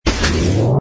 engine_ku_fighter_start.wav